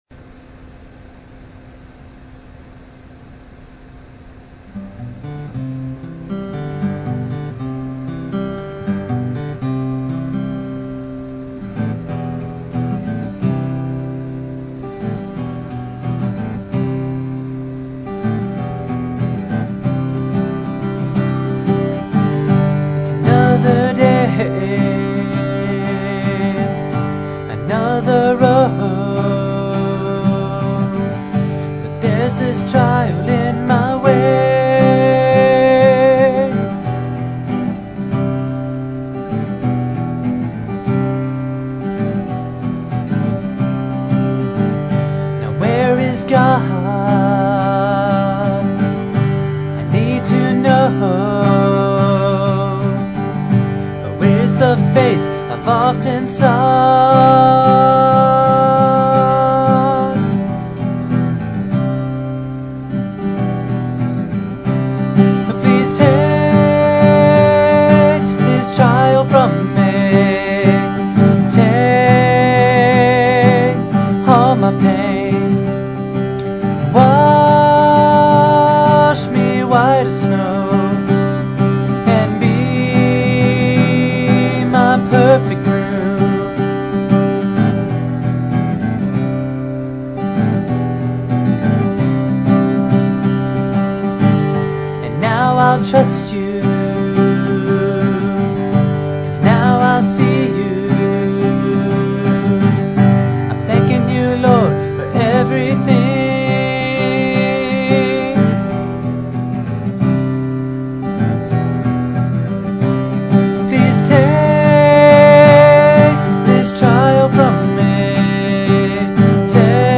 The songs on the site are rough cuts.